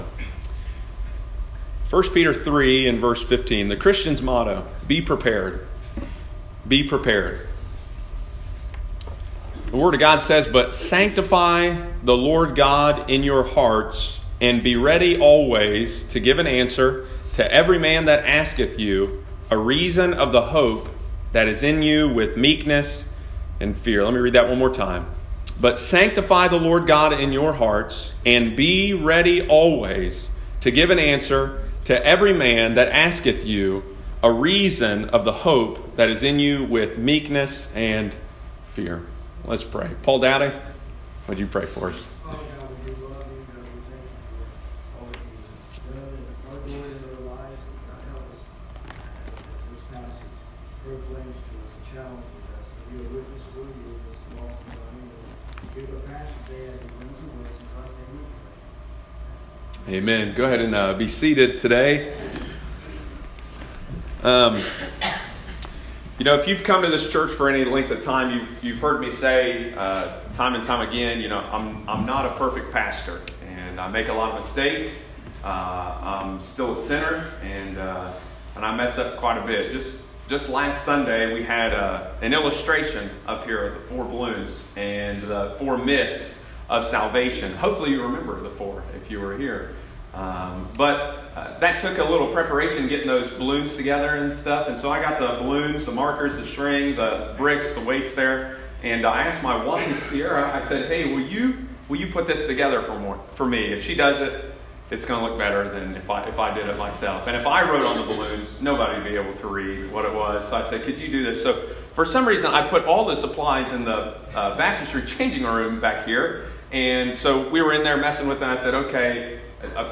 Sunday morning, July 14, 2019.